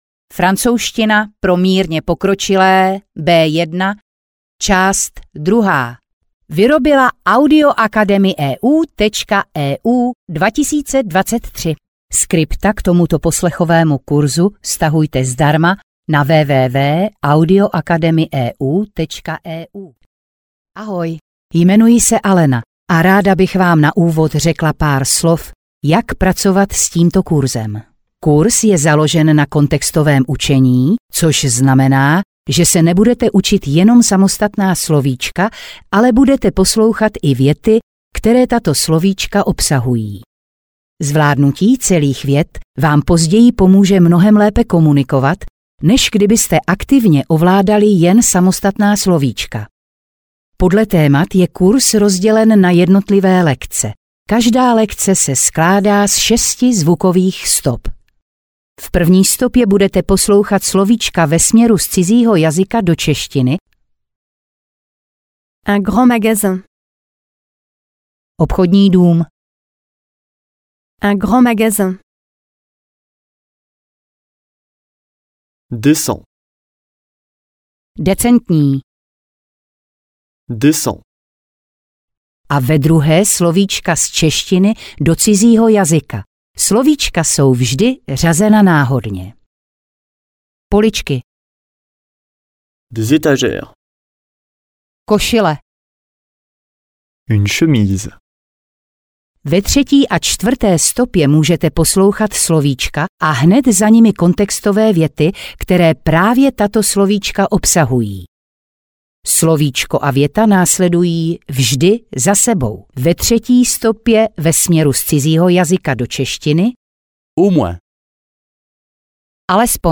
Francouzština pro mírně pokročilé B1 - část 2 audiokniha
Ukázka z knihy